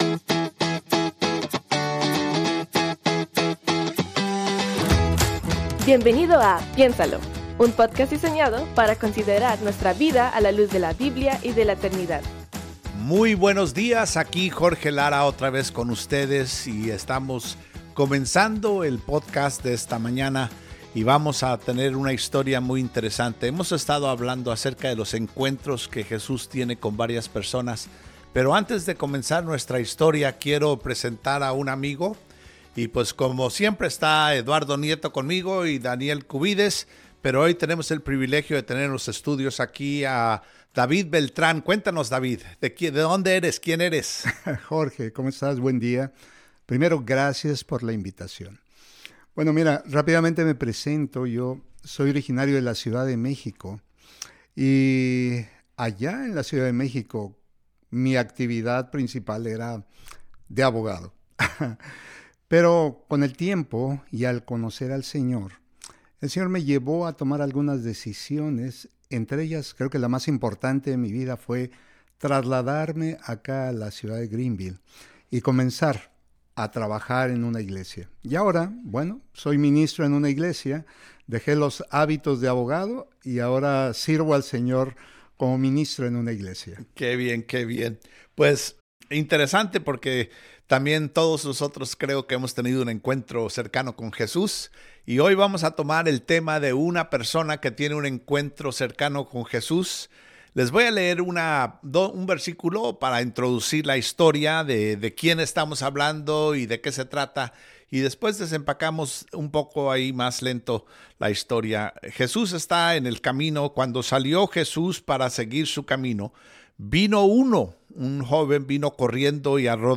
Únete a nuestra conversación acerca del encuentro que tuvo Jesús con un joven rico. Un hombre que prefirió sus riquezas a la vida eterna.